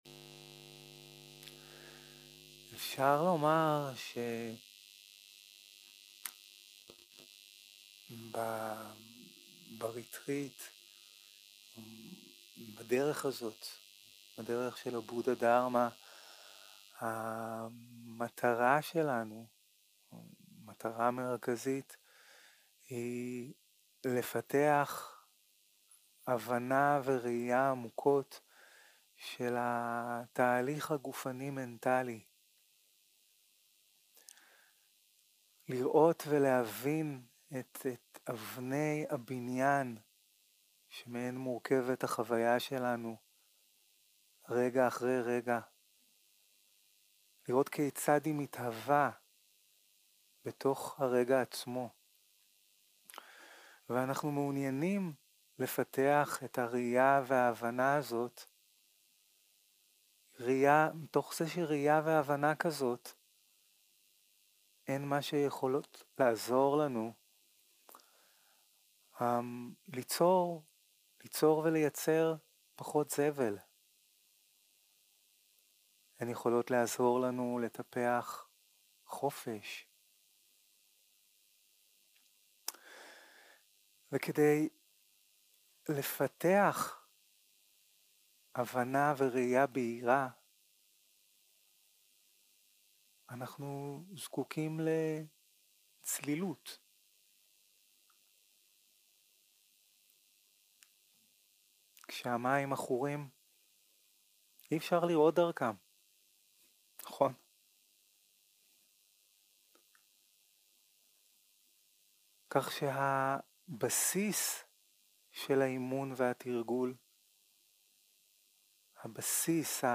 יום 2 - הקלטה 2 - בוקר - הנחיות למדיטציה - למה נשימה, תשומת לב לנשימה 0:00 0:00 סוג ההקלטה: Dharma type: Guided meditation שפת ההקלטה: Dharma talk language: Hebrew